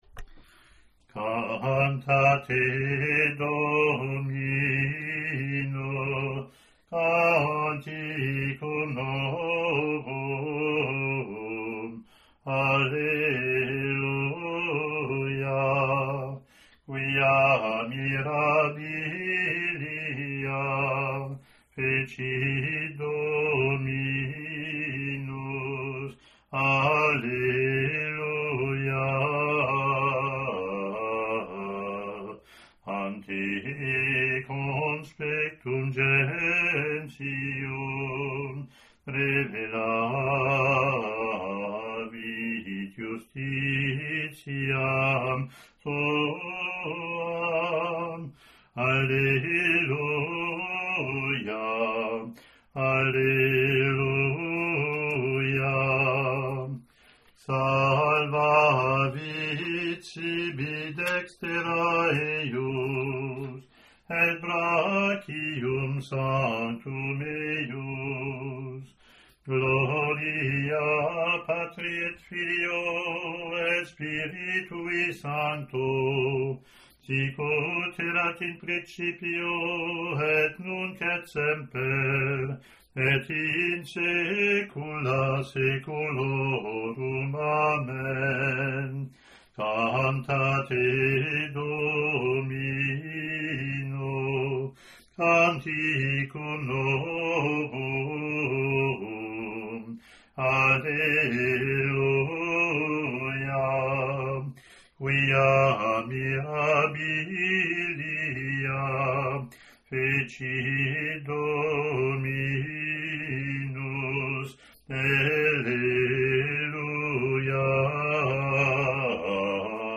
Latin antiphon, verse, Gloria patri)